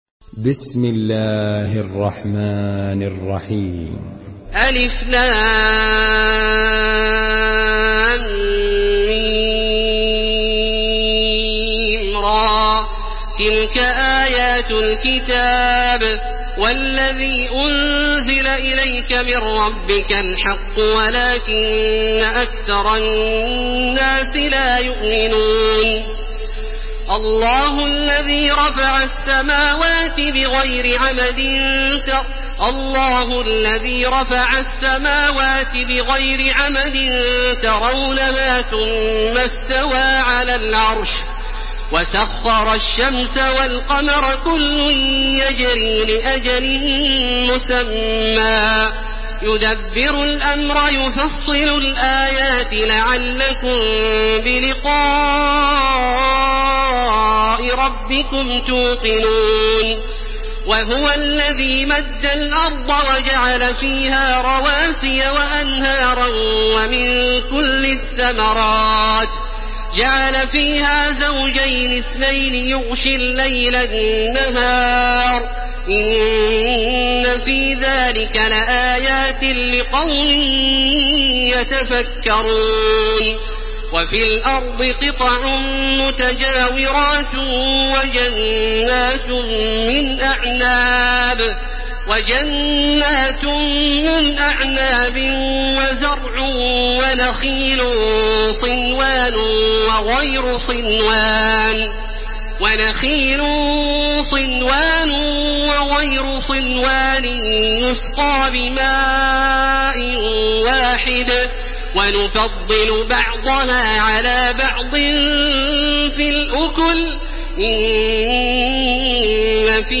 Surah Ar-Rad MP3 in the Voice of Makkah Taraweeh 1431 in Hafs Narration
Listen and download the full recitation in MP3 format via direct and fast links in multiple qualities to your mobile phone.